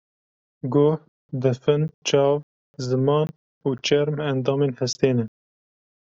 Read more skin (outer covering of living tissue of a person) Frequency B2 Pronounced as (IPA) /t͡ʃɛɾm/ Etymology Cognate with Persian چرم In summary Related to Persian چرم (čarm).